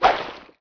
rabble_rock1.wav